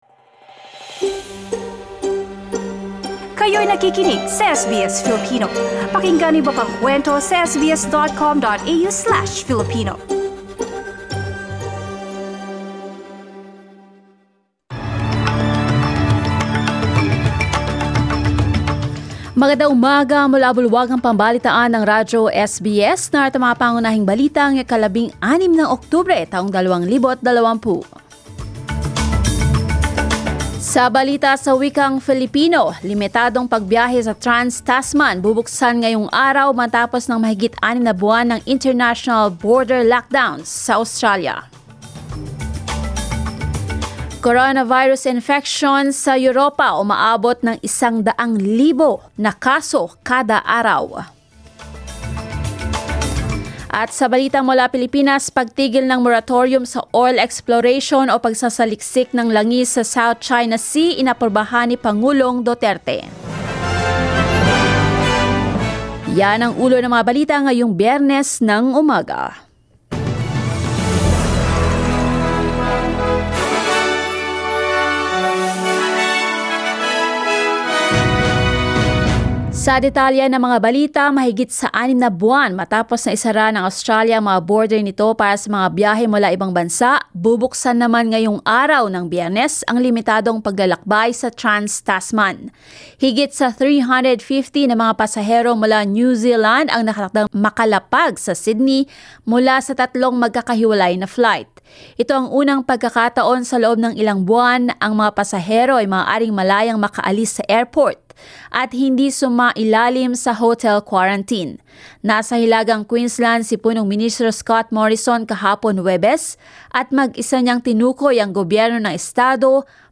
SBS News in Filipino, Friday 16 October